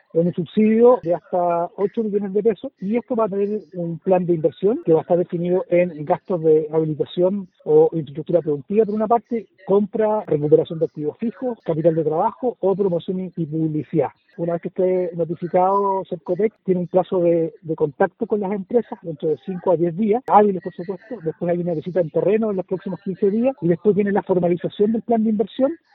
El seremi de Economía en la zona, Luis Cárdenas, abordó la aplicación de la ficha que busca entregar un subsidio de hasta 8 millones de pesos a emprendimientos afectados.